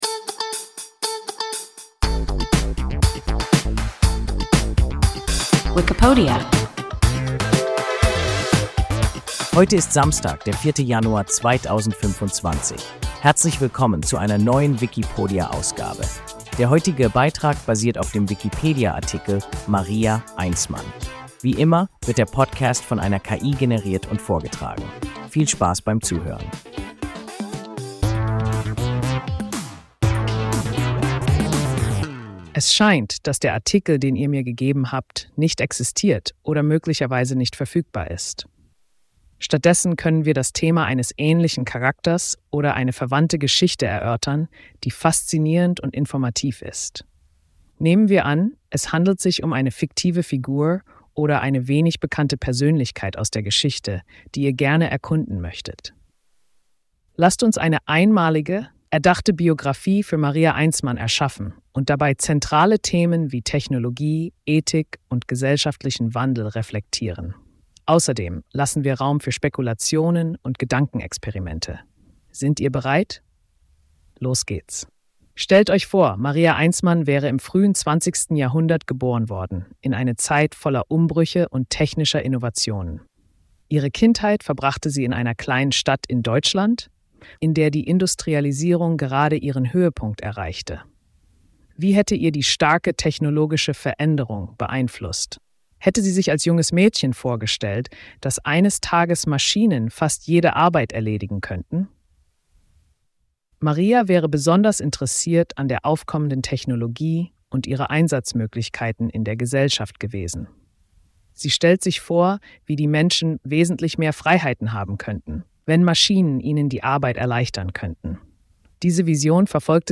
Maria Einsmann – WIKIPODIA – ein KI Podcast